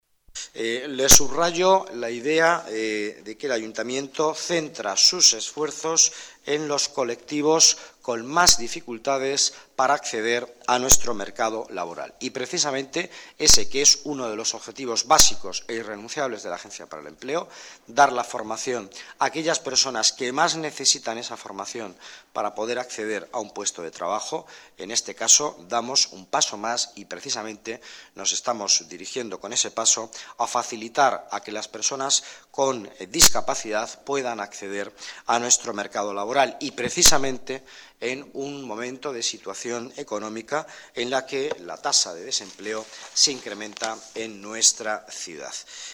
Nueva ventana:Declaraciones de Miguel Ángel Villanueva durante la firma del convenio